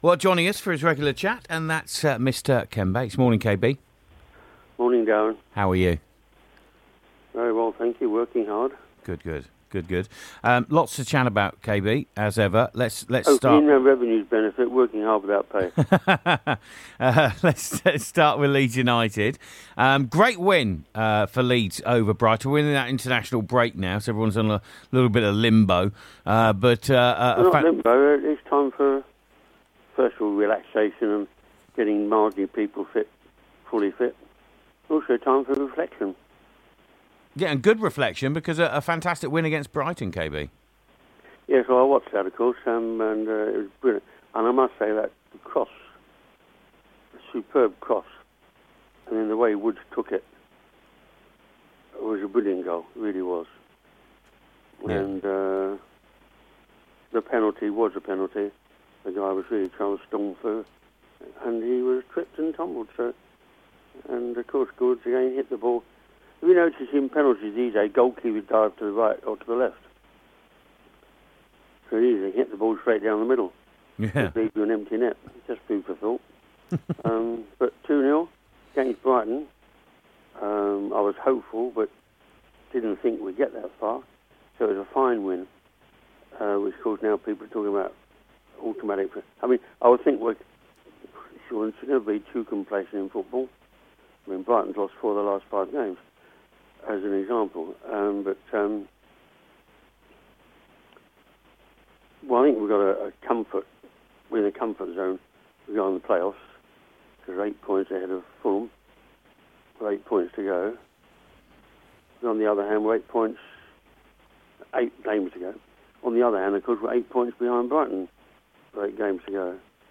The Ken Bates Interview